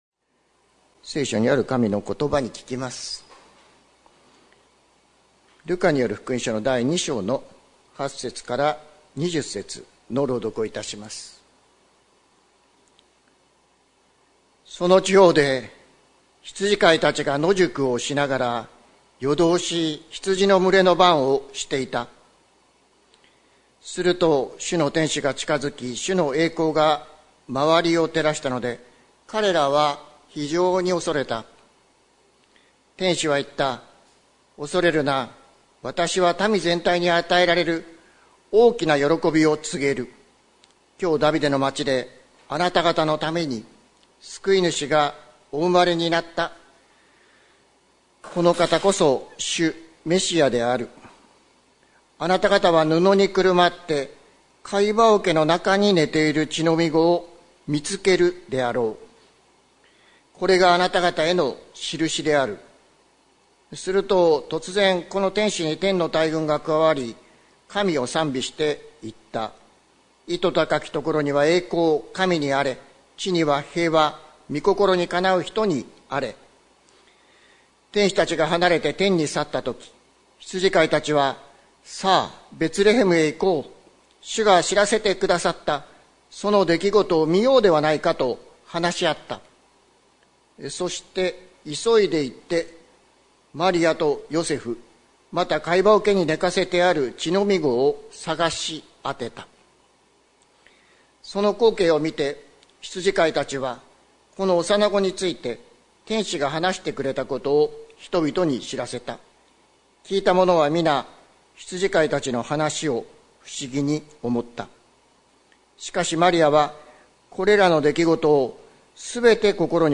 説教アーカイブ。
録音の最後に、 関教会でクリスマス・イブの夜に歌い続けてきた讃美歌の録音もはいっています